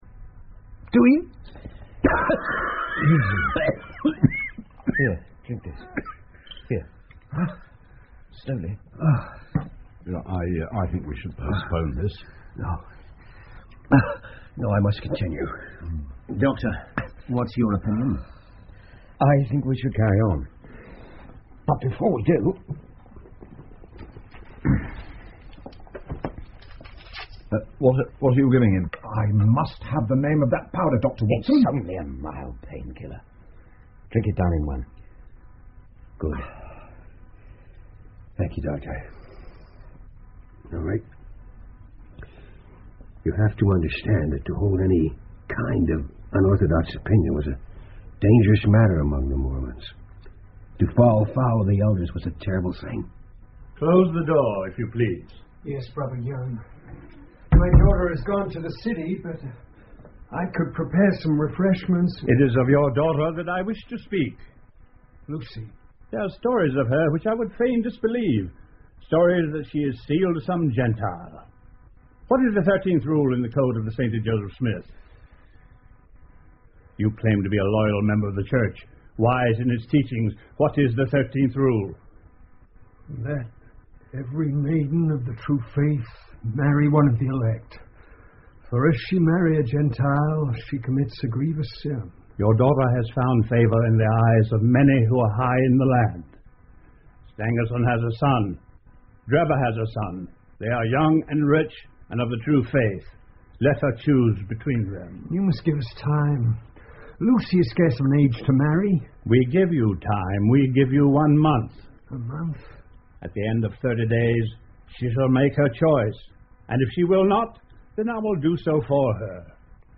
福尔摩斯广播剧 A Study In Scarlet 血字的研究 18 听力文件下载—在线英语听力室